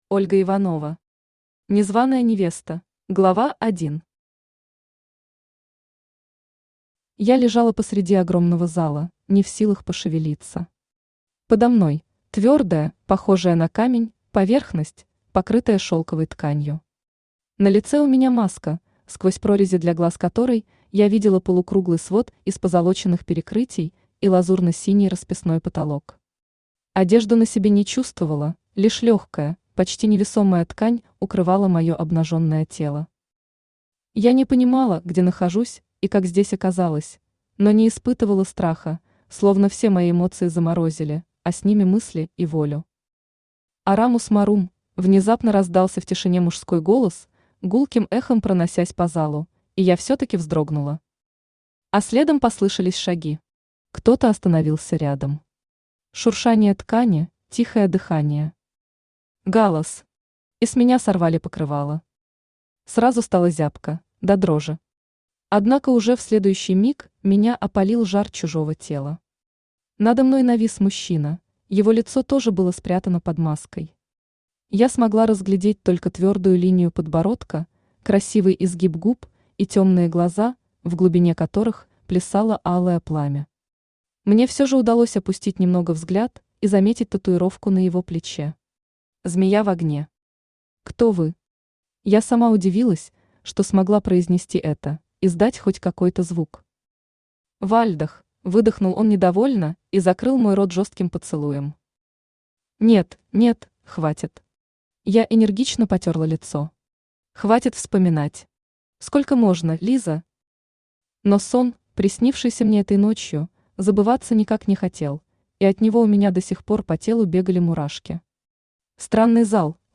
Аудиокнига Незваная невеста | Библиотека аудиокниг
Aудиокнига Незваная невеста Автор Ольга Дмитриевна Иванова Читает аудиокнигу Авточтец ЛитРес.